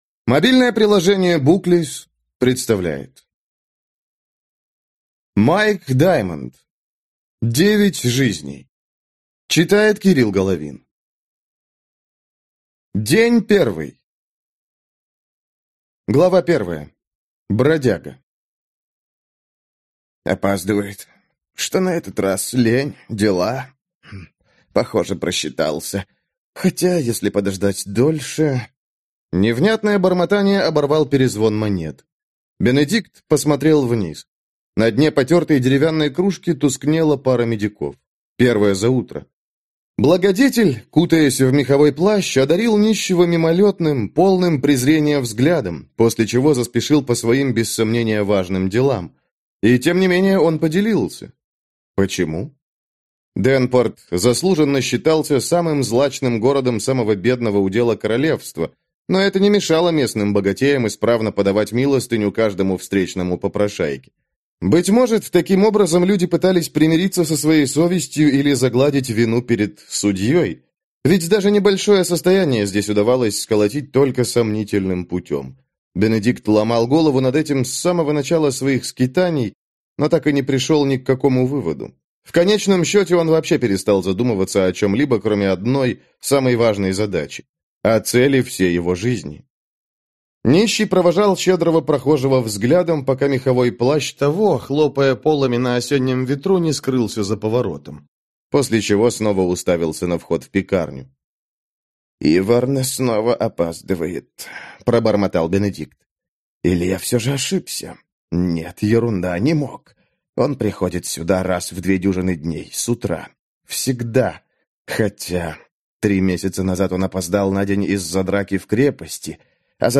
Аудиокнига Девять жизней | Библиотека аудиокниг